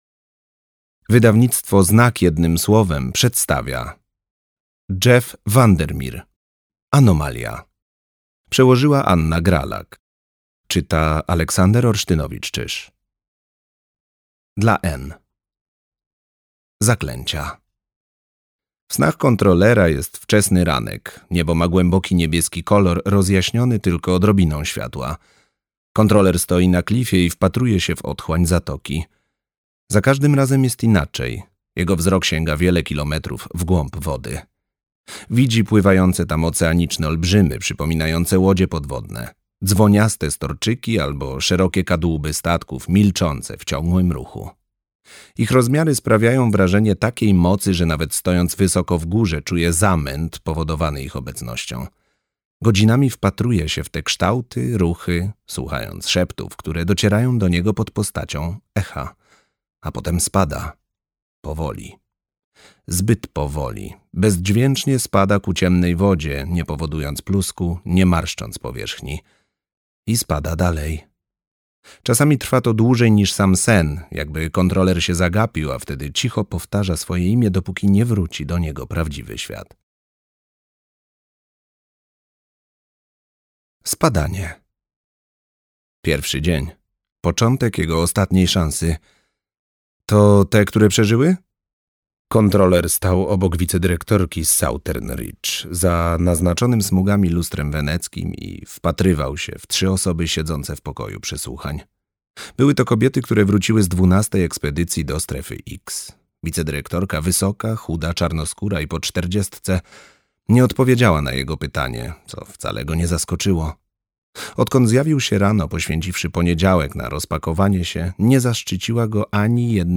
Anomalia - Jeff VanderMeer - audiobook